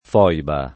foiba [ f 0 iba ] s. f.